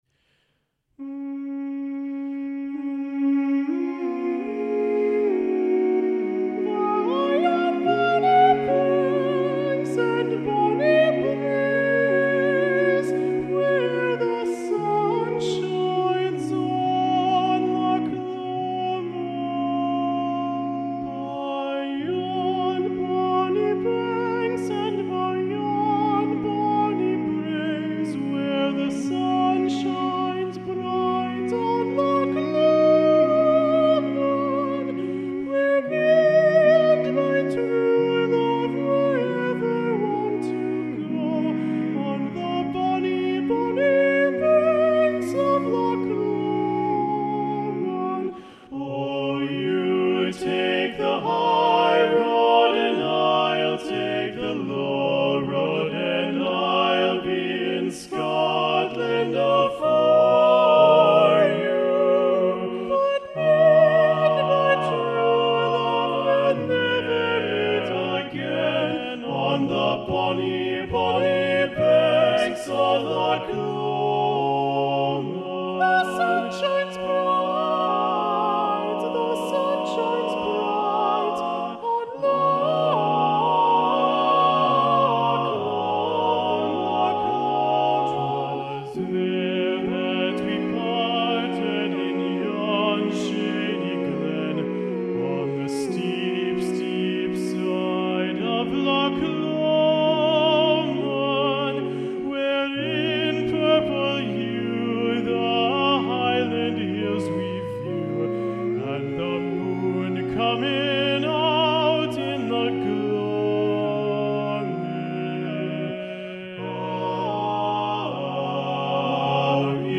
an arrangement of the Scottish folk song